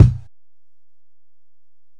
Kick (21).wav